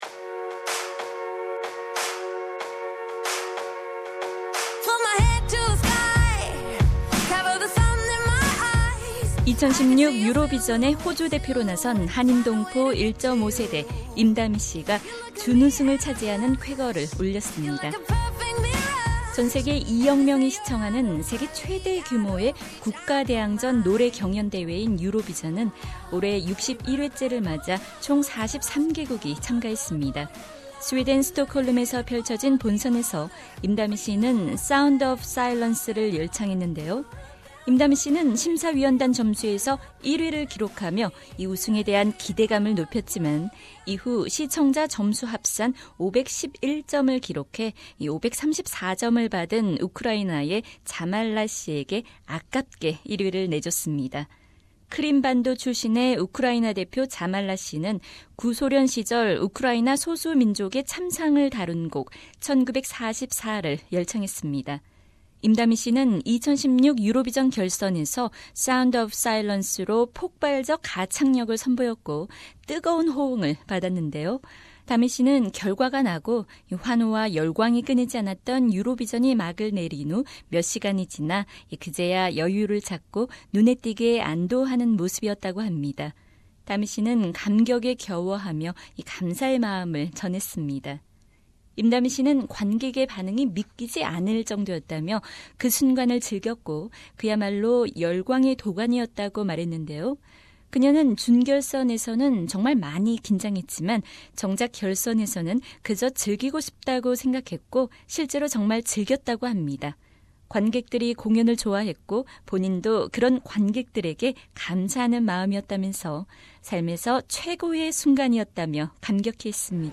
Australia's Dami Im gives an emotional thank you after Eurovision 2016 Grand Final